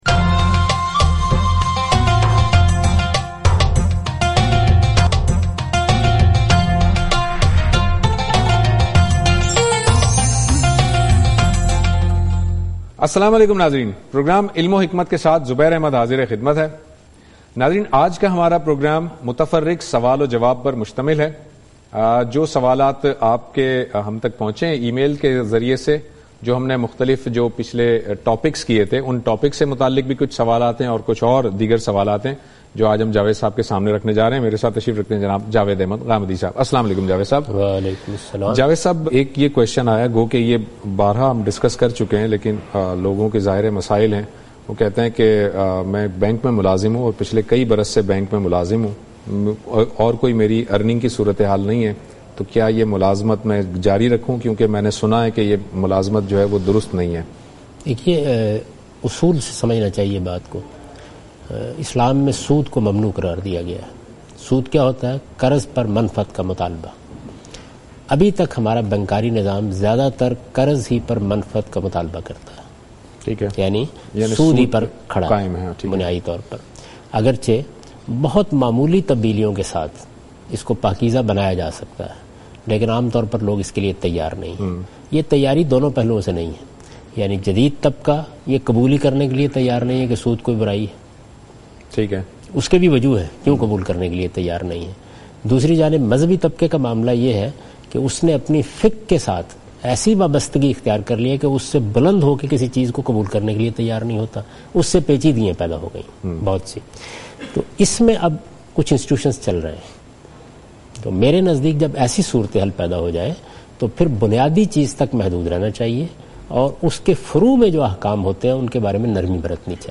In this program Javed Ahmad Ghamidi answers miscellaneous questions.